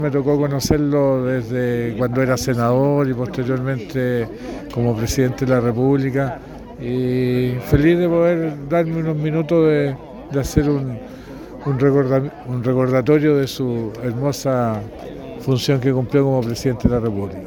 Tal es el caso del alcalde de Mariquina, Rolando Mitre, quien recordó su relación con el exmandatario.